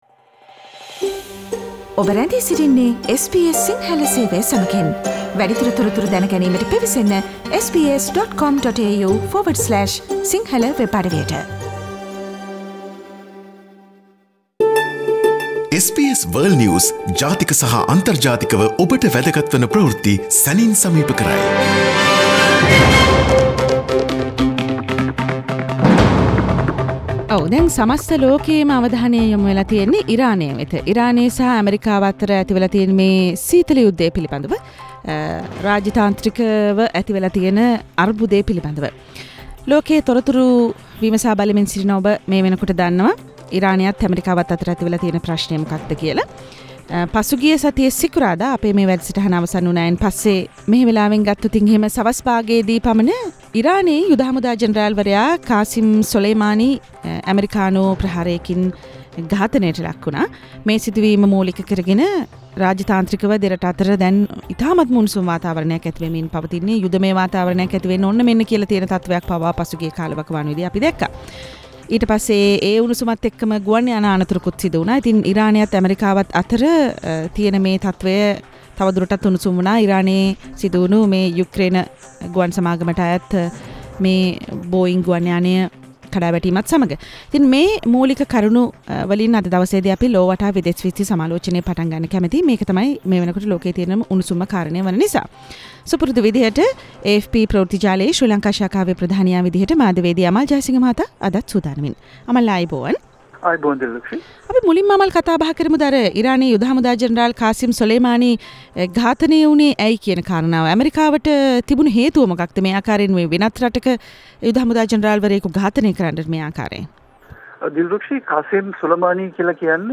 SBS Sinhala world news wrap Source: SBS Sinhala